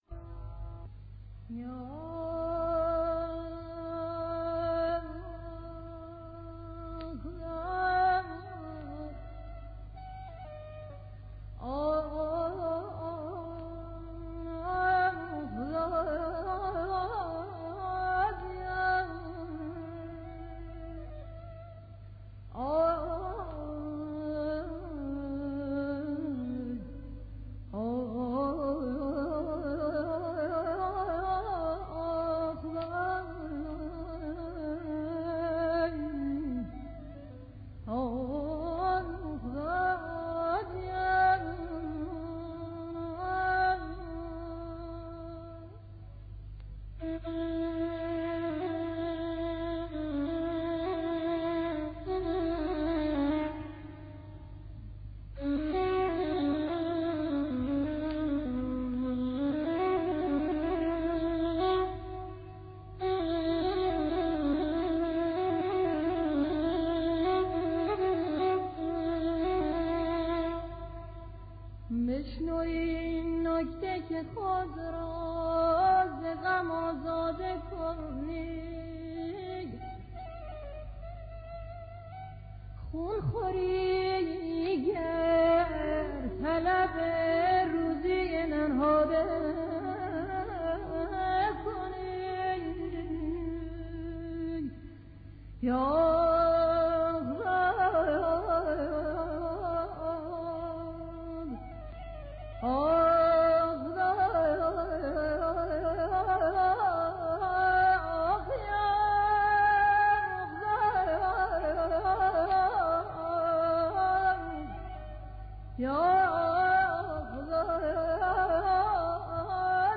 parisa_avaz_bayat_tork.mp3